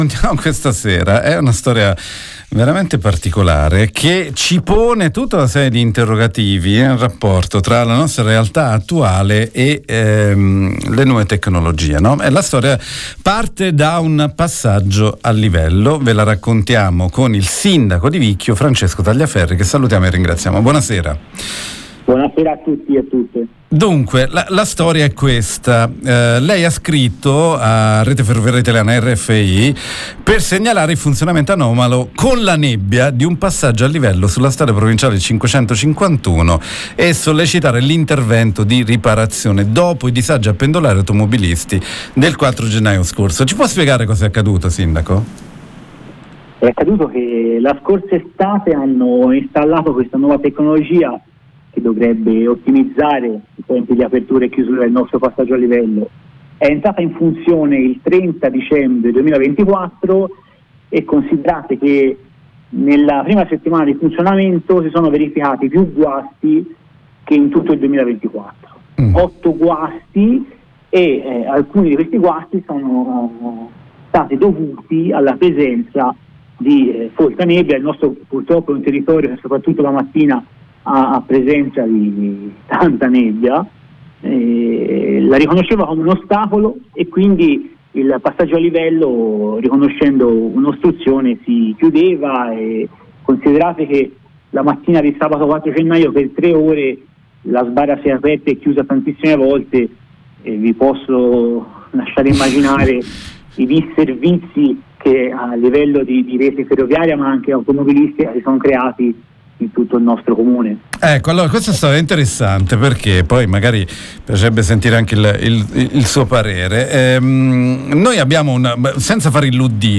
Il passaggio a livello di Vicchio Il sindaco di Vicchio (Firenze), Francesco Tagliaferri, ha scritto a Rfi per segnalare il funzionamento anomalo con la nebbia di un passaggio a livello sulla provinciale 551 e sollecitare l’intervento di riparazione dopo i disagi a pendolari e automobilisti che ci sono stati il 4 gennaio scorso. Tagliaferri ha chiesto a Rfi un rapido intervento. lo abbiamo intervistato In particolare, sul passaggio a livello, riporta una nota, “è stata installata di recente una moderna tecnologia per la sicurezza del traffico stradale e ferroviario” ma “la presenza di folta nebbia sembrerebbe all’origine dell’episodio: il sistema intelligente avrebbe rilevato la fitta coltre […]